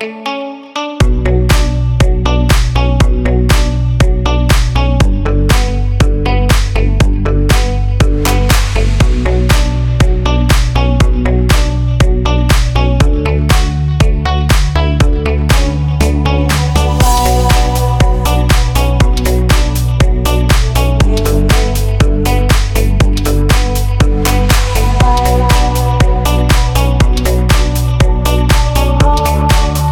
• House